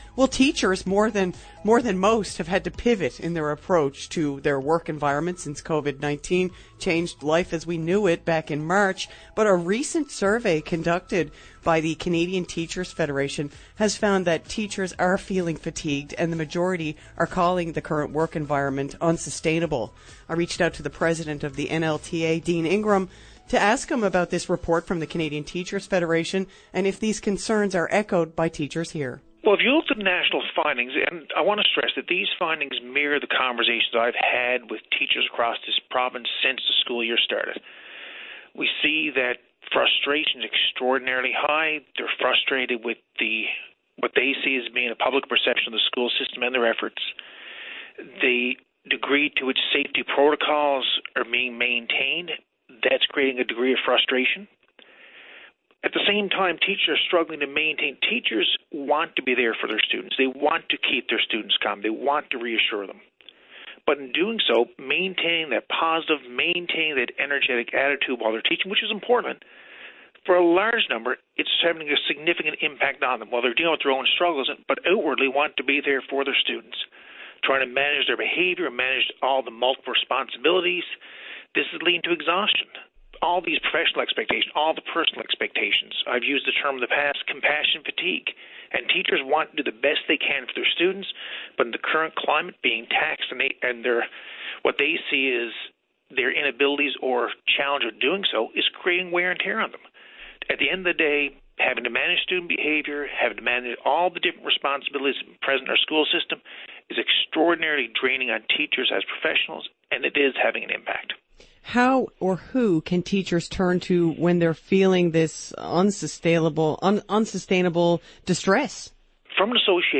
Media Interview - VOCM Morning Show Dec 10, 2020